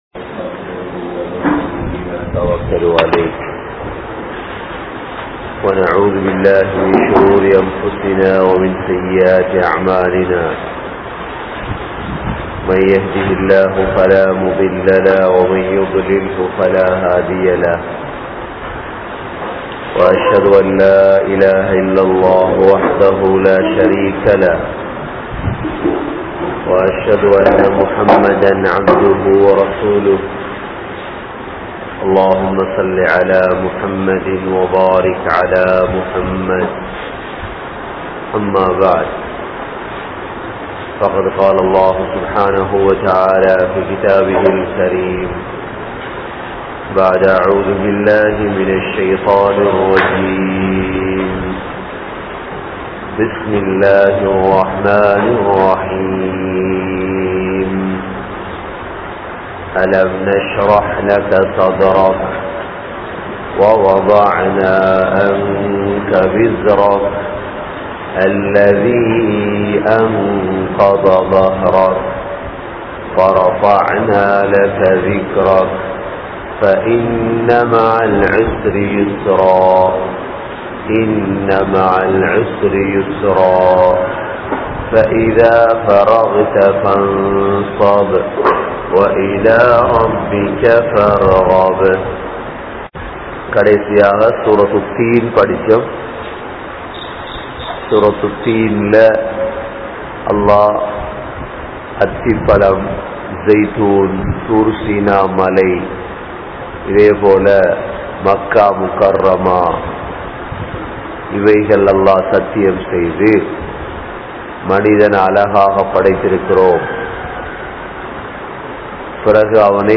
Surah Sharah | Audio Bayans | All Ceylon Muslim Youth Community | Addalaichenai
Meera Masjith(Therupalli)